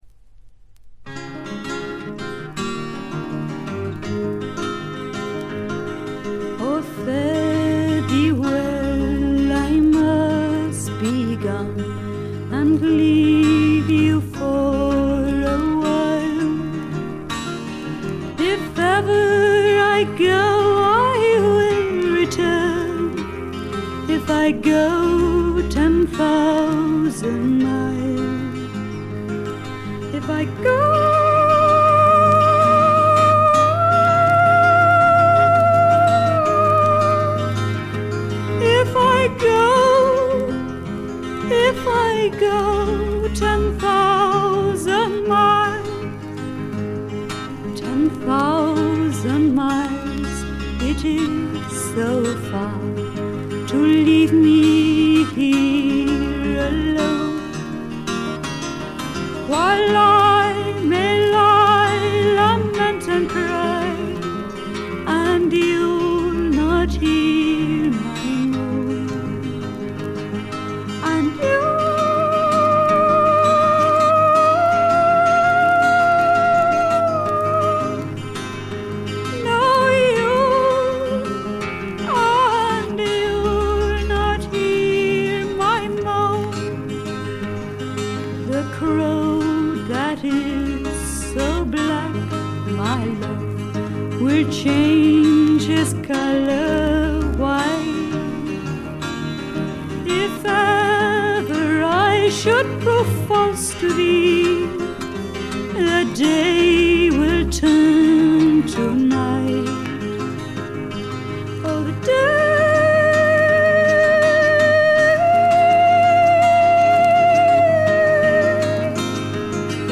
軽微なバックグラウンドノイズ程度。
英国フィメール・フォークの大名作でもあります。
内容はというとほとんどがトラディショナル・ソングで、シンプルなアレンジに乗せた初々しい少女の息遣いがたまらない逸品です。
モノラル盤です。
試聴曲は現品からの取り込み音源です。